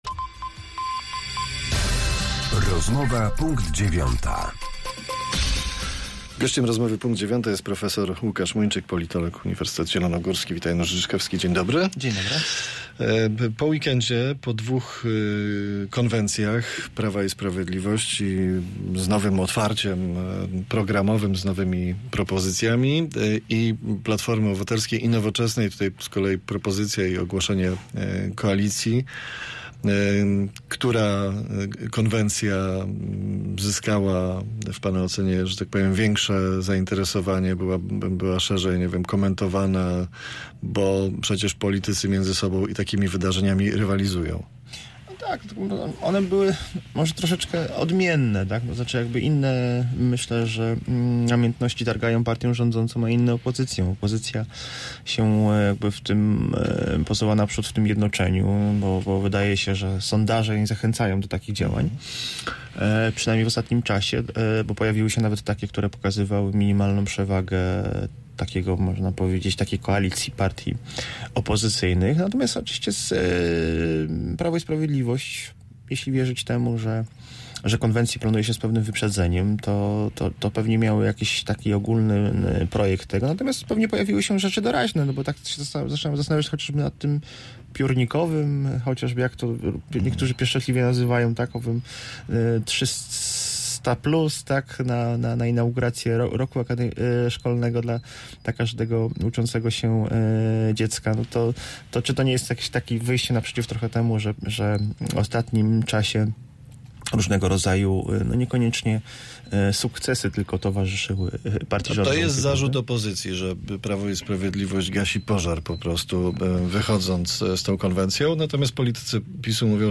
Z politologiem